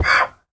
Minecraft Version Minecraft Version latest Latest Release | Latest Snapshot latest / assets / minecraft / sounds / mob / horse / donkey / hit3.ogg Compare With Compare With Latest Release | Latest Snapshot